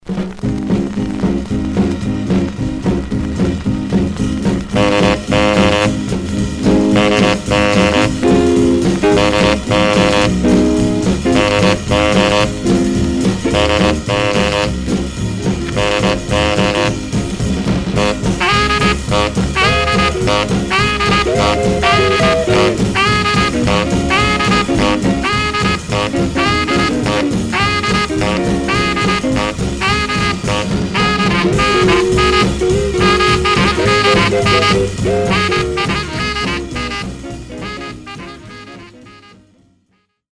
Surface noise/wear Stereo/mono Mono
R&B Instrumental